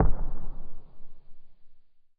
explosion_far_distant_07.wav